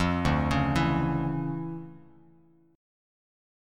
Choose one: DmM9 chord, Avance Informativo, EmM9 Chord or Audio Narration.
DmM9 chord